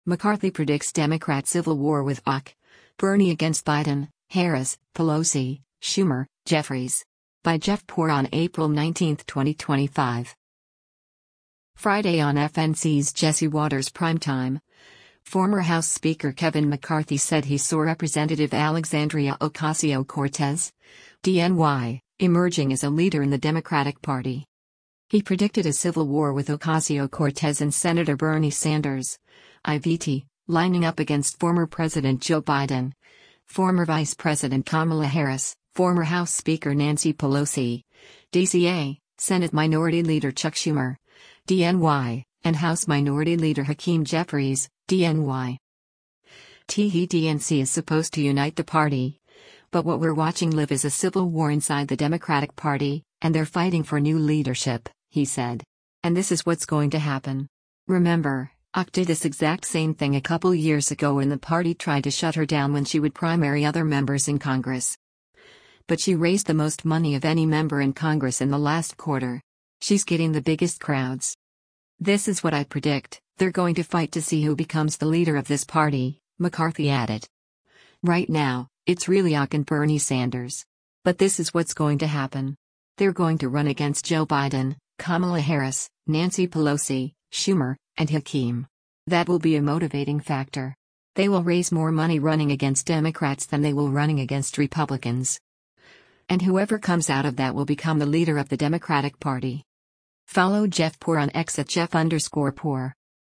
Friday on FNC’s “Jesse Watters Primetime,” former House Speaker Kevin McCarthy said he saw Rep. Alexandria Ocasio-Cortez (D-NY) emerging as a leader in the Democratic Party.